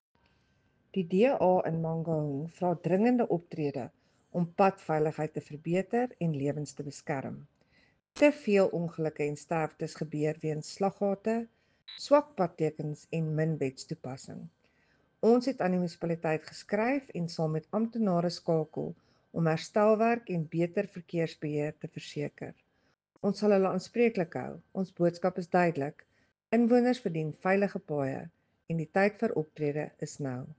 Afrikaans soundbites by Cllr Selmé Pretorius and